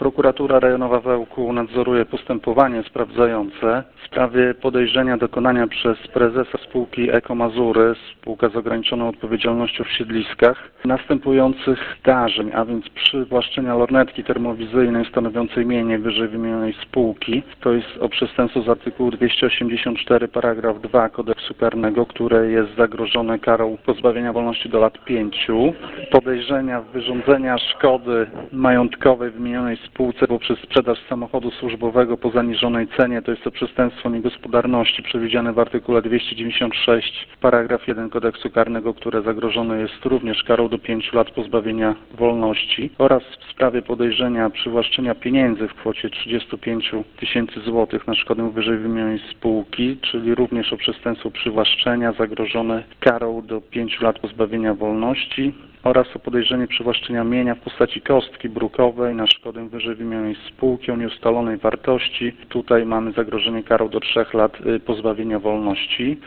Czego dokładnie dotyczy postępowanie wyjaśnia prokurator Piktel.